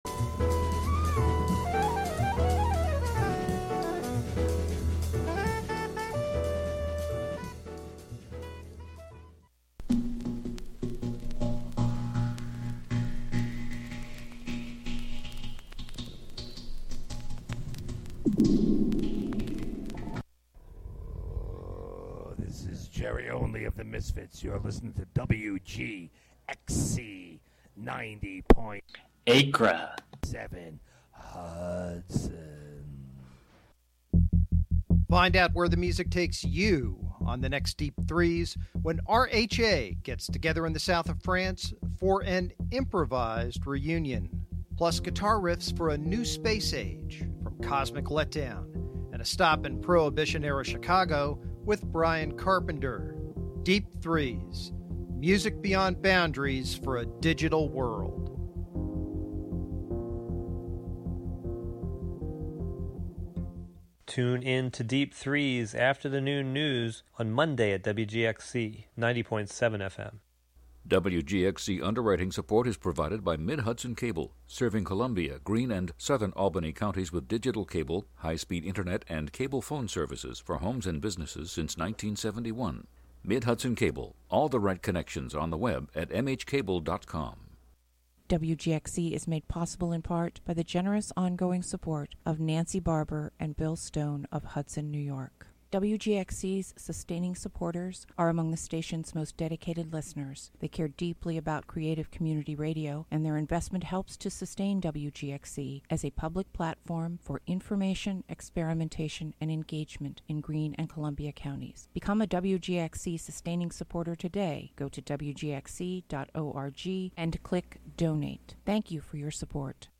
Counting down ten new sounds, stories, or songs, "American Top 40"-style.